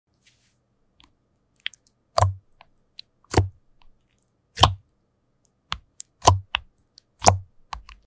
Slime Keyboard ASMR Sounds sound effects free download